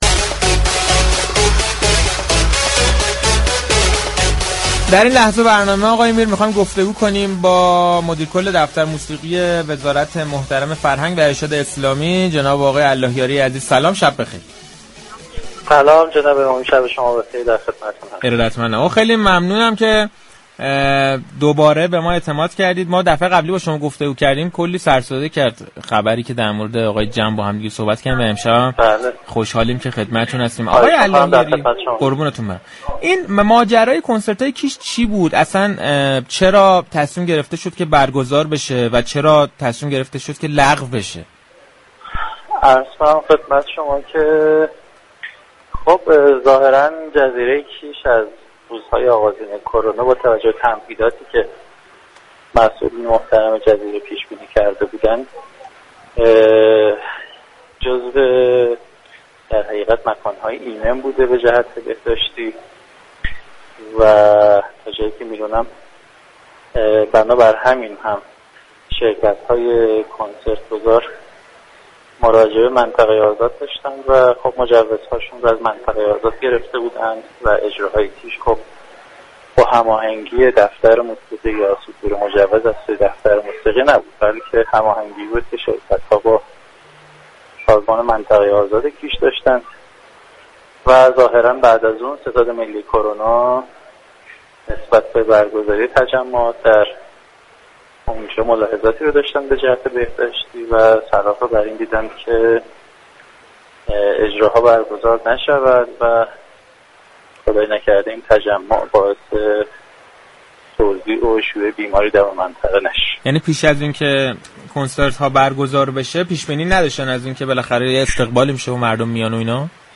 محمد الهیاری در گفتگو با برنامه صحنه